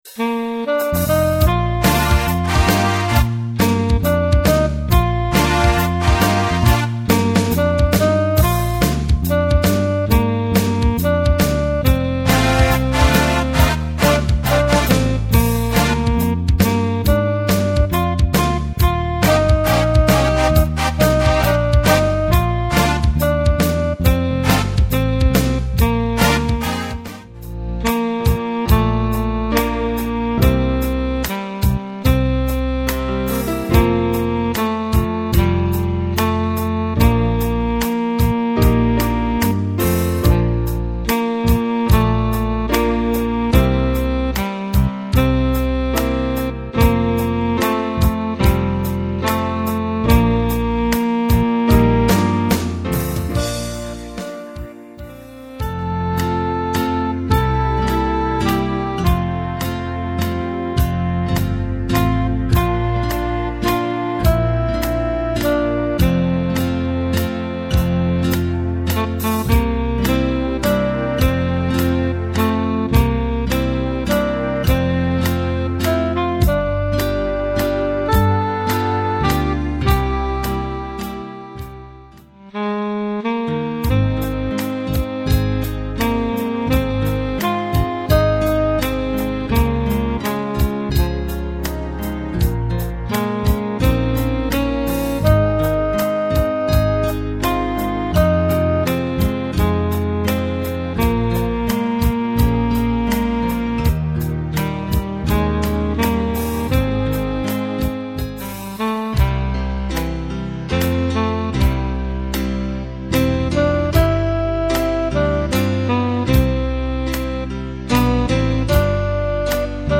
Course Songs Sample